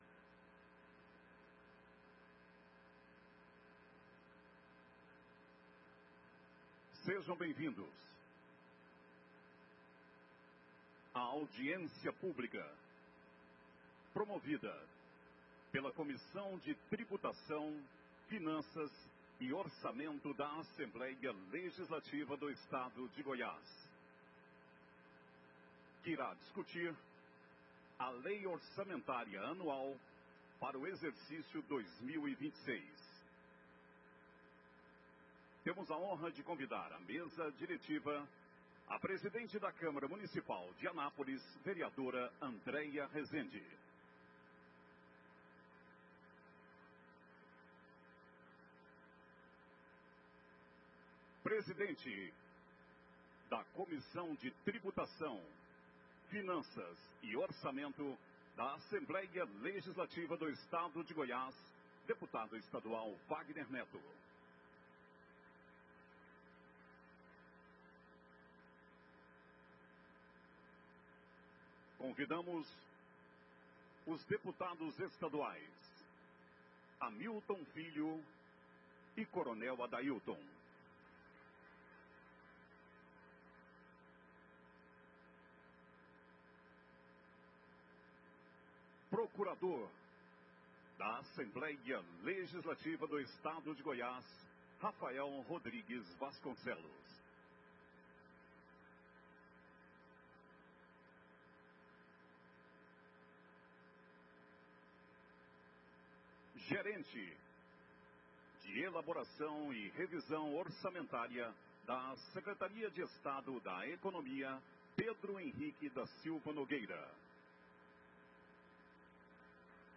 Audiência Pública Lei Orçamentária - Alego Anual Dia 18/11/2025